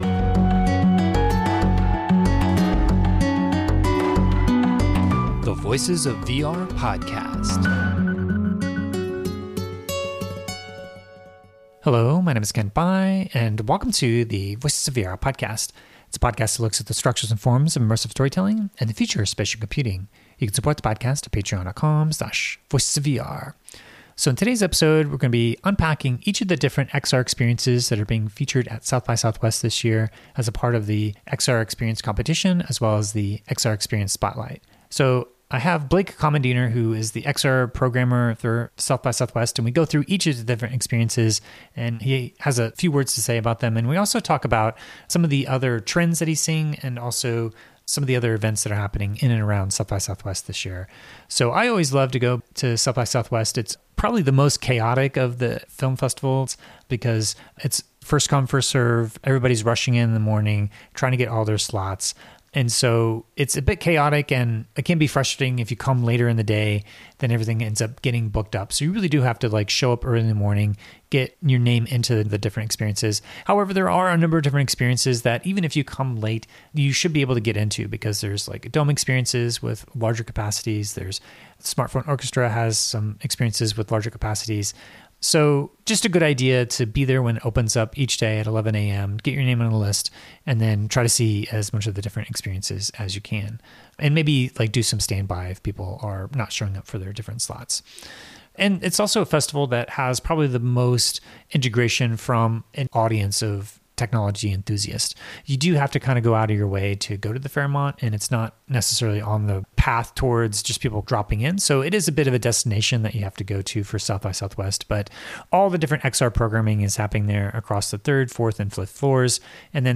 Here is a list of the XR experiences being shown at SXSW, and I will update this post as I post my Voices of VR podcast interviews as I've featured a dozen projects in my previous coverage.